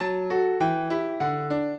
piano
minuet11-2.wav